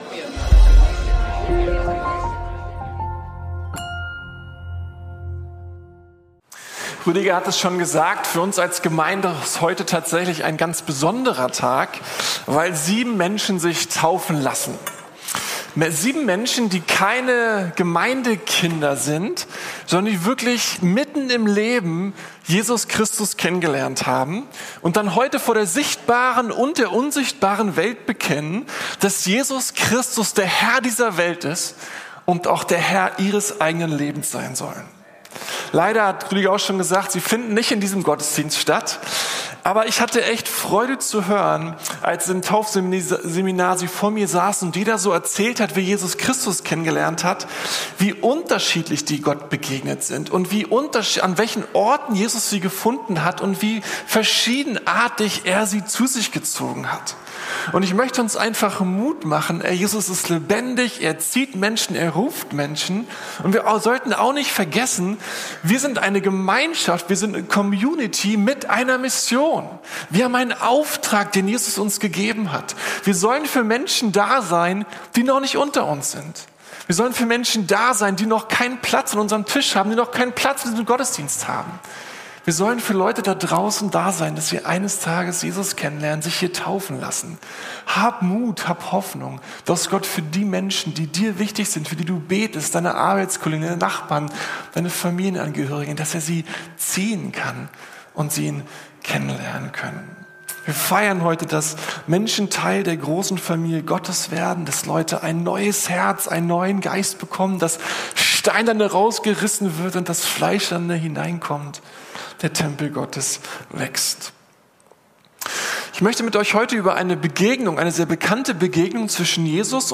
Marta und Maria ~ Predigten der LUKAS GEMEINDE Podcast